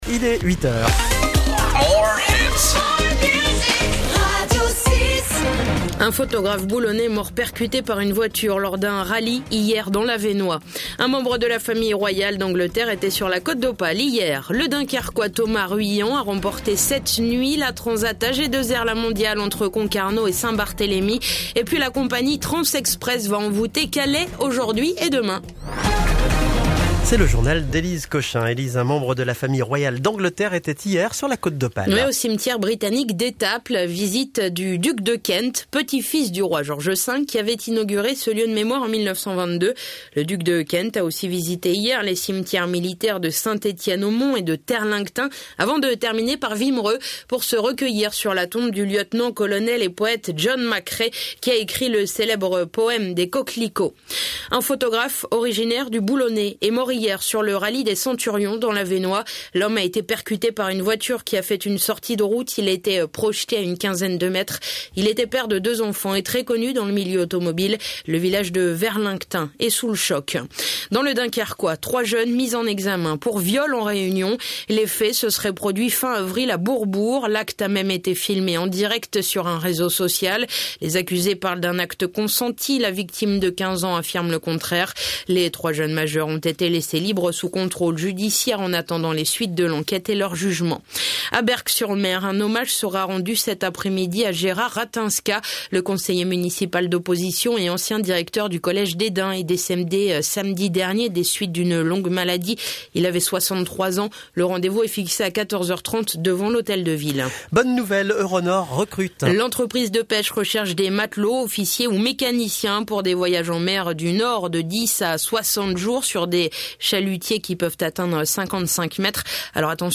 Le journal côte d'opale du vendredi 11 mai